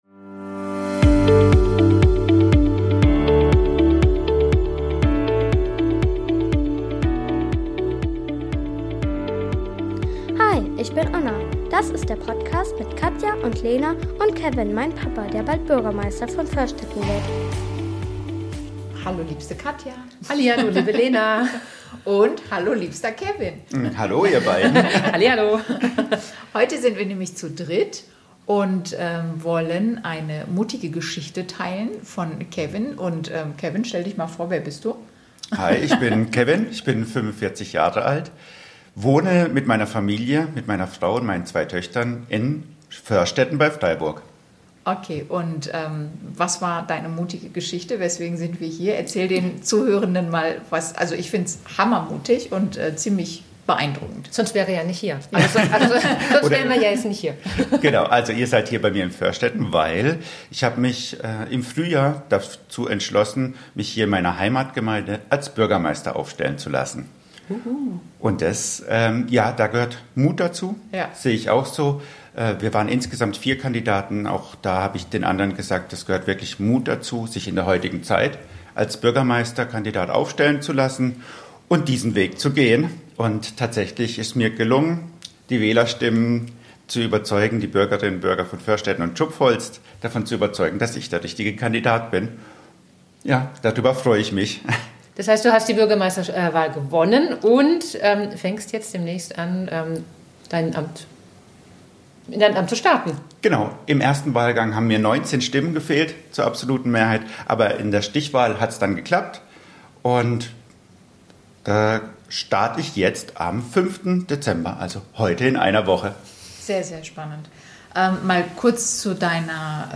Im Interview erzählt Kevin offen: • warum er sich überhaupt zur Kandidatur entschlossen hat • welche Tage er sich jetzt schon bewusst frei genommen hat – und was das über seinen Führungsstil verrät • wie er sich vor Amtsantritt Zeit genommen hat, um mit allen Mitarbeitenden persönlich und in Ruhe zu sprechen • welche Vorbereitungen er trifft, um gut in die Verantwortung zu starten • wie er mit Druck, Erwartungen und den Herausforderungen einer Wahl umgeht Diese Episode zeigt eindrucksvoll, wie viel Mut es braucht, sich sichtbar zu machen, Verantwortung zu übernehmen und den eigenen Weg zu gehen. Kevin nimmt uns mit hinter die Kulissen – von der Kandidatur über den Wahlkampf bis zum Start in sein neues Amt.